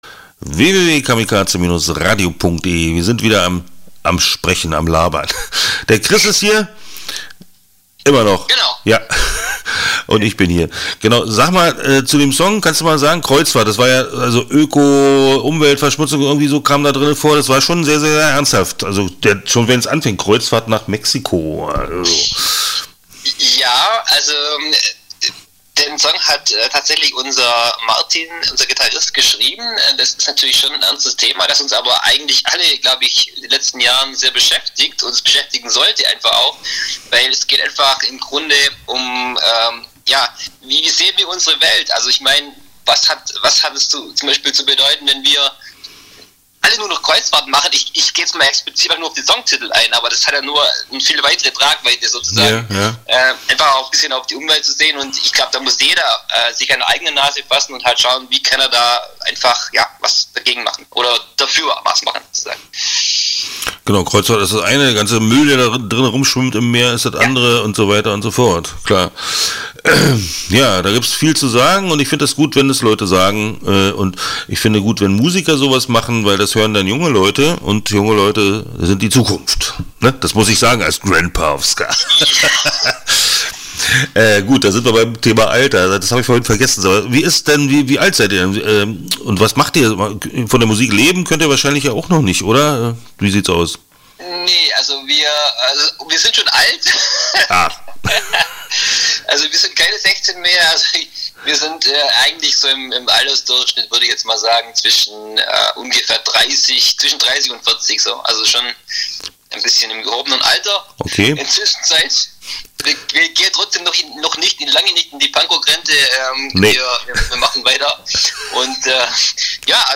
Start » Interviews » Escandalos aus Laupheim